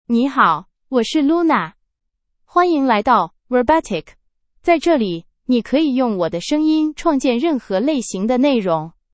LunaFemale Chinese AI voice
Luna is a female AI voice for Chinese (Mandarin, Simplified).
Voice sample
Listen to Luna's female Chinese voice.
Female